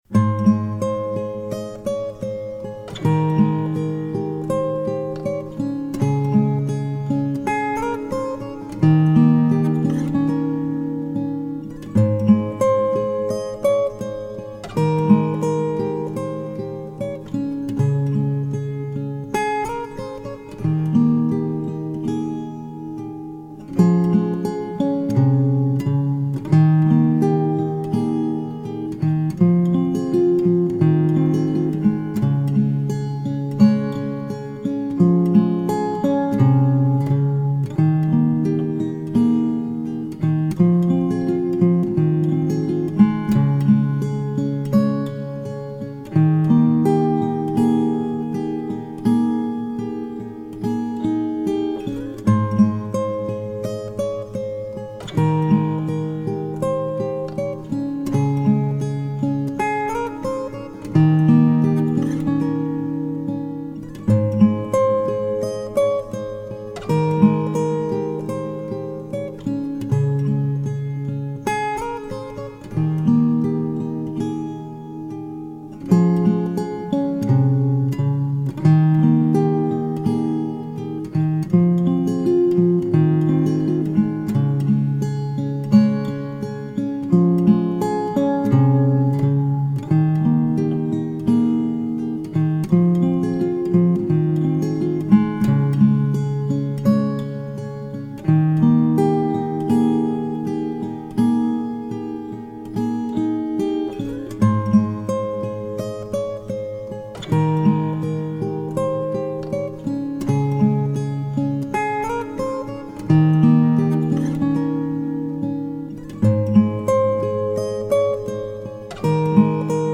موسیقی بی کلام آرامش بخش عاشقانه گیتار